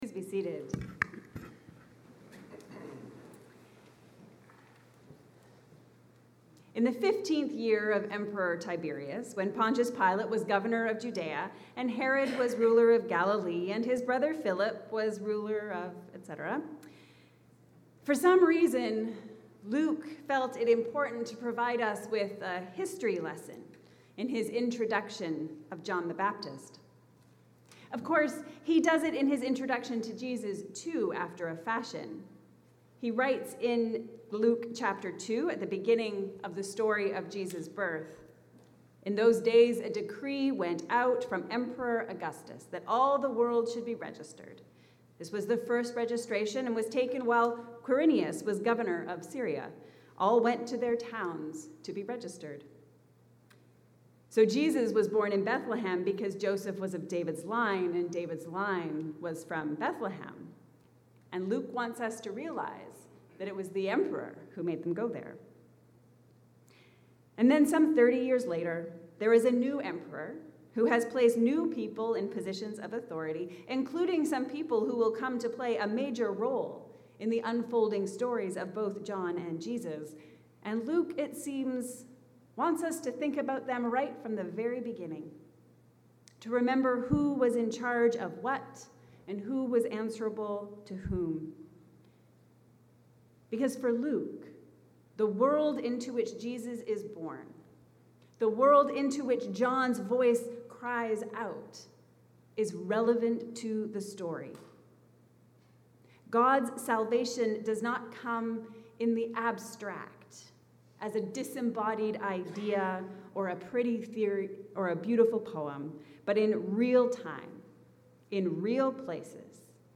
The day is coming. In fact, the day comes. Sermon for the Second Sunday of Advent